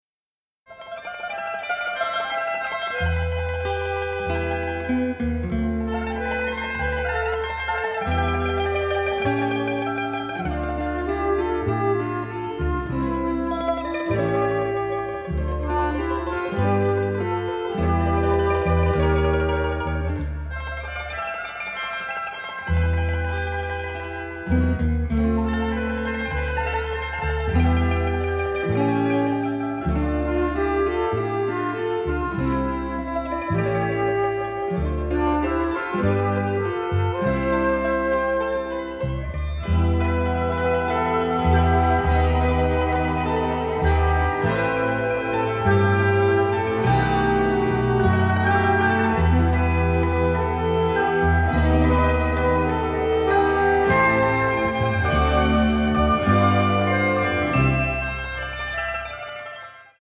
soundtrack album
builds upon classic Italian songs
original stereo session elements